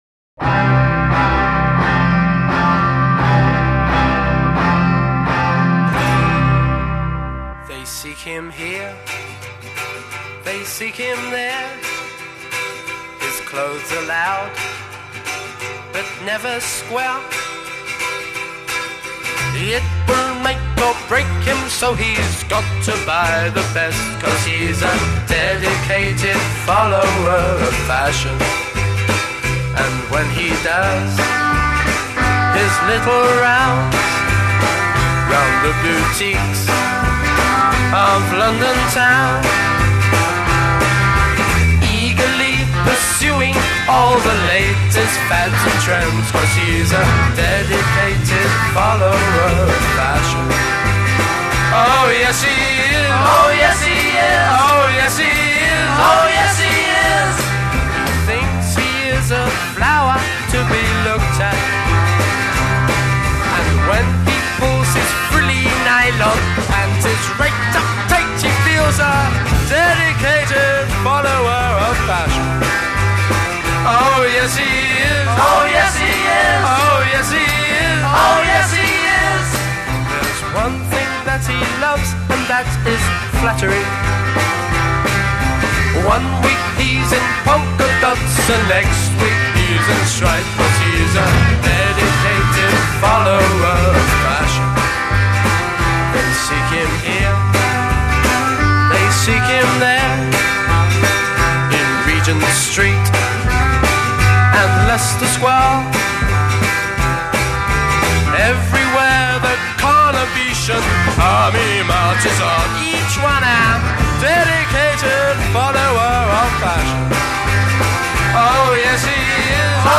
guitar and lead vocal
bass
percussion
drums
piano
Intro 0:00   guitar chords
A verse :   As above with added guitar responses. b
B chorus :   Begins with choral responses;
coda :   Repeat hook twice more (three times altogether)
end with guitar chords from intro..